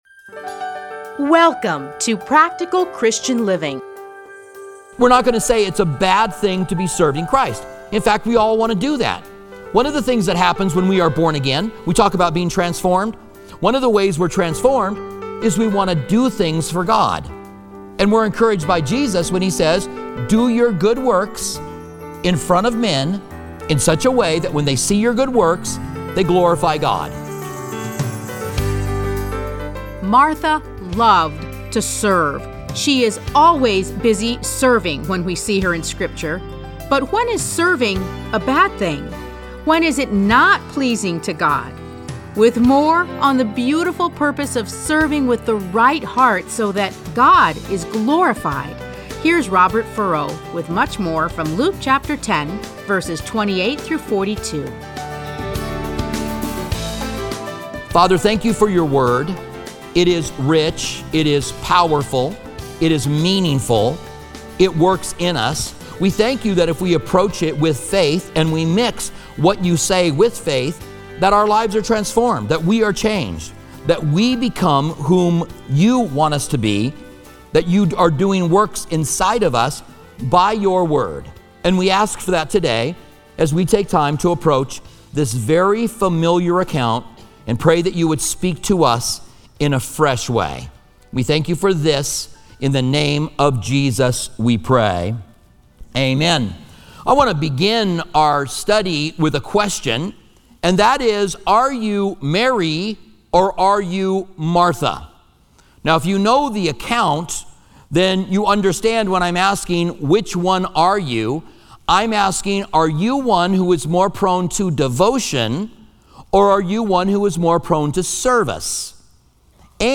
Listen to a teaching from Luke Luke 10:38-42 Playlists A Study in Luke Download Audio